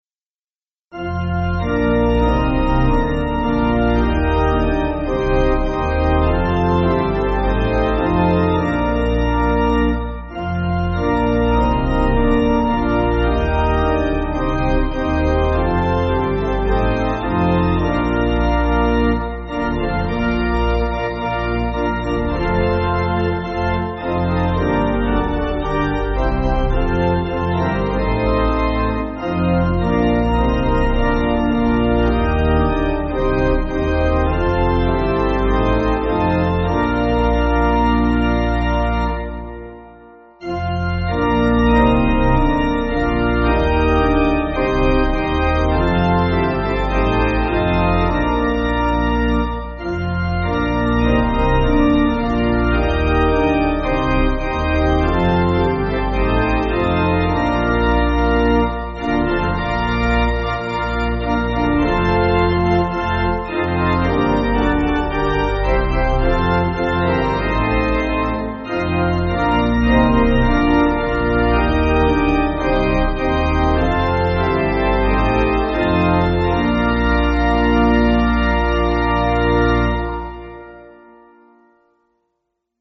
Organ
(CM)   2/Bb